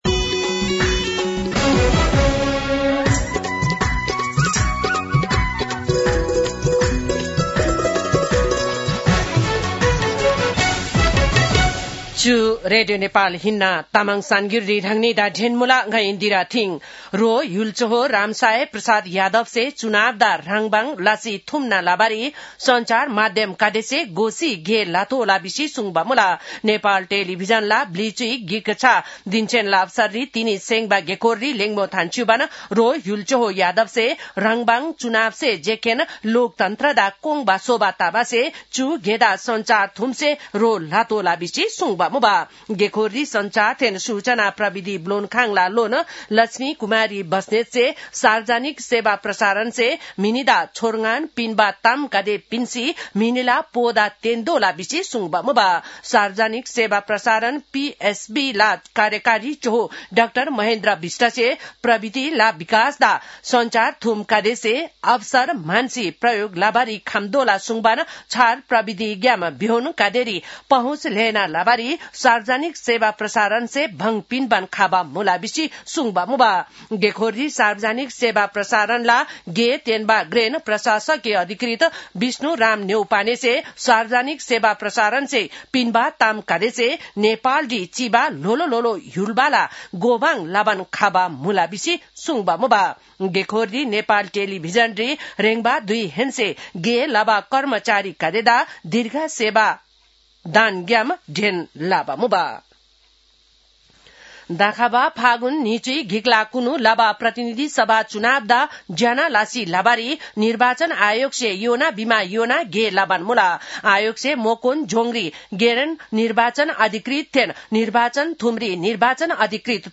तामाङ भाषाको समाचार : १७ माघ , २०८२